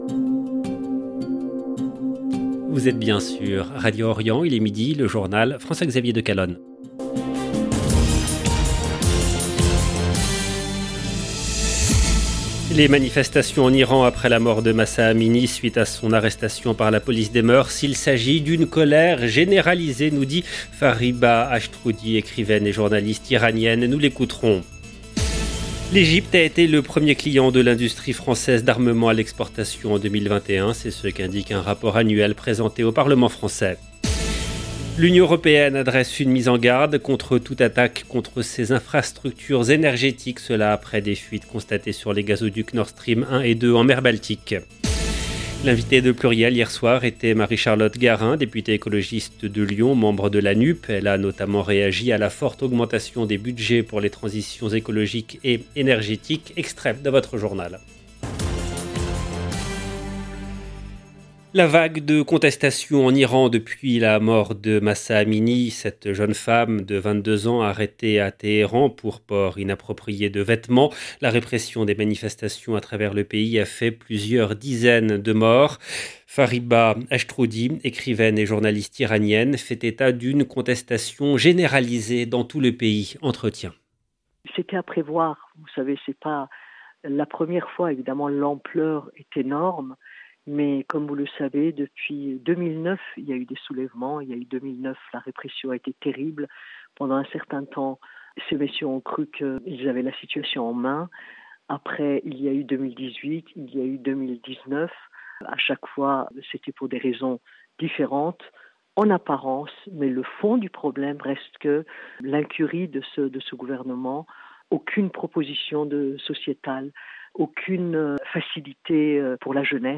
EDITION DU JOURNAL DE 12H EN LANGUE FRANCAISE DU 28/9/2022